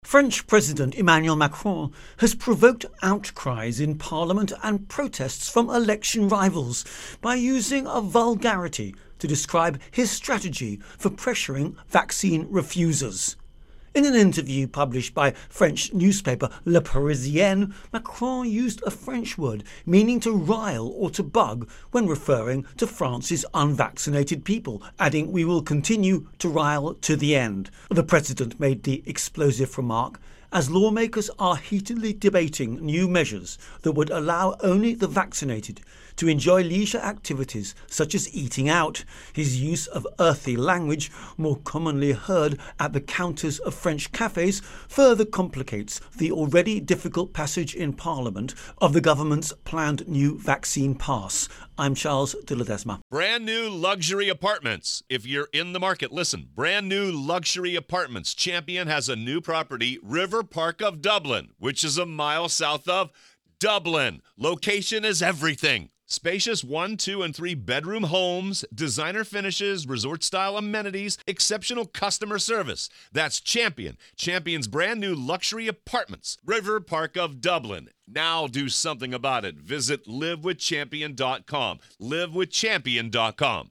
Intro and Voicer on Virus Outbreak-France-Macron